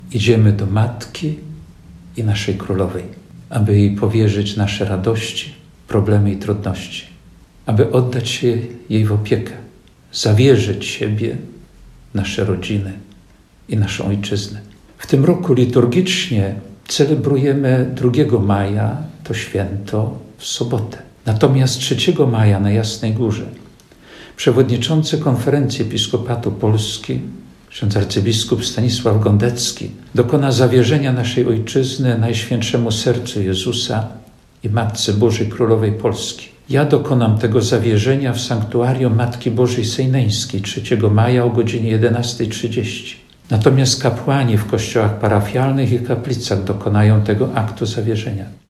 – Uroczystość Najświętszej Maryi Panny Królowej Polski to nasze święto narodowe – mówi ks biskup Jerzy Mazur – ordynariusz diecezji ełckiej